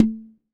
Index of /90_sSampleCDs/EdgeSounds - Drum Mashines VOL-1/CZ-DRUMS KIT
CZHCONGA.wav